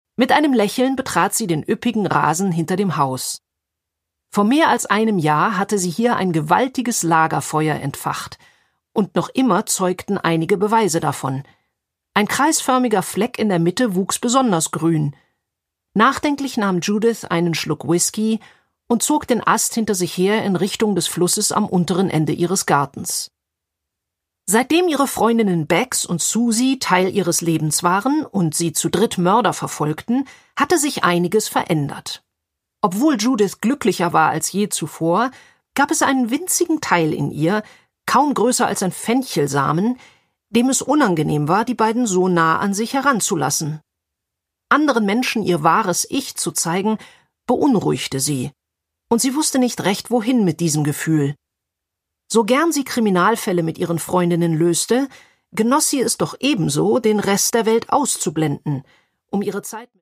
Produkttyp: Hörbuch-Download
Gelesen von: Christine Prayon